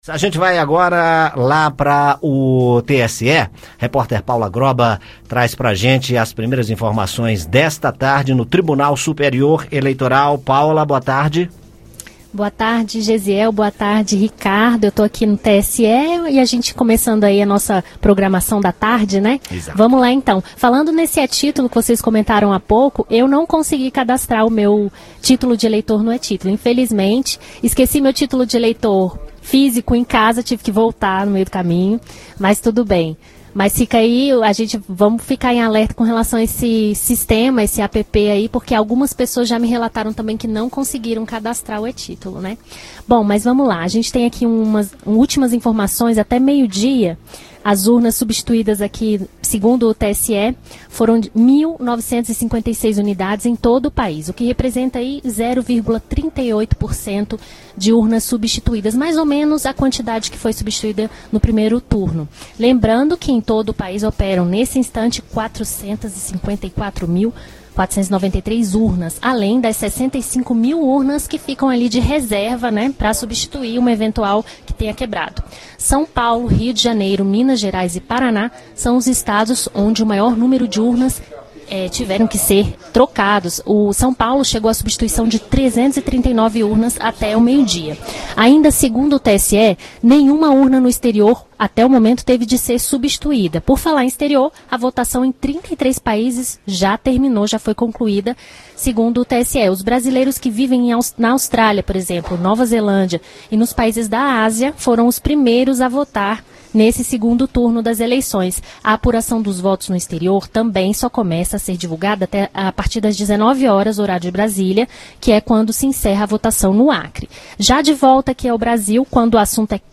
As informações direto do TSE com a repórter